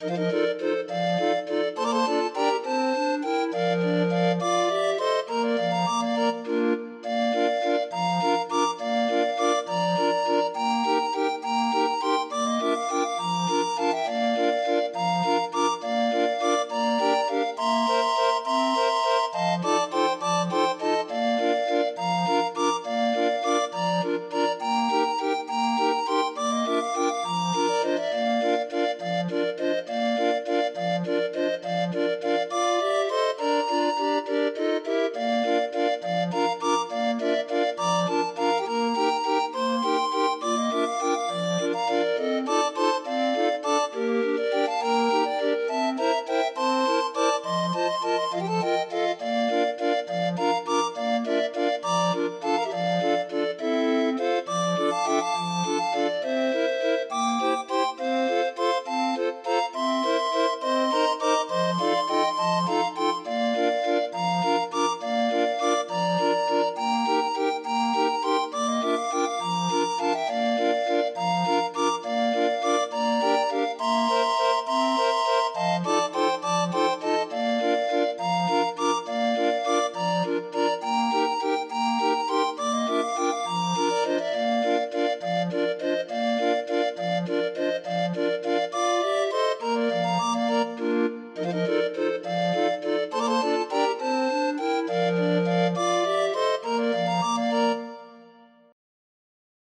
Demo of 20 note MIDI file